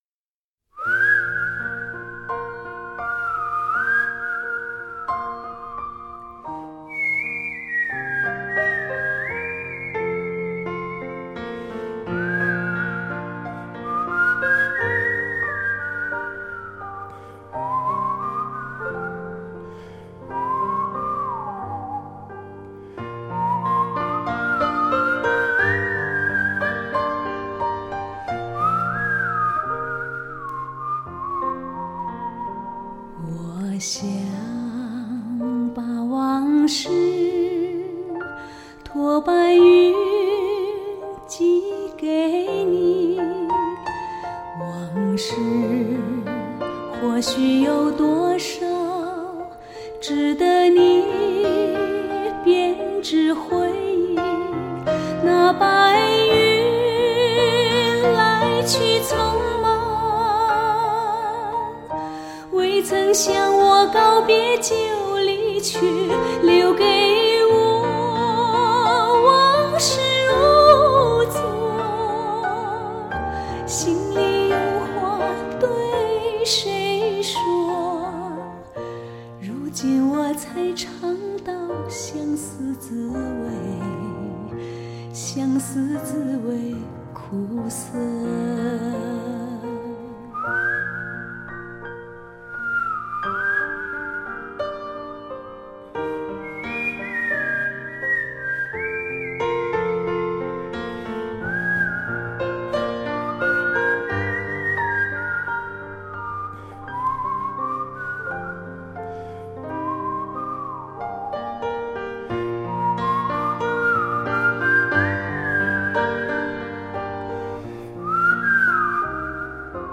地方民歌、戏曲
香港版HDCD
钢琴、手风琴
中阮
口哨
小提琴
大提琴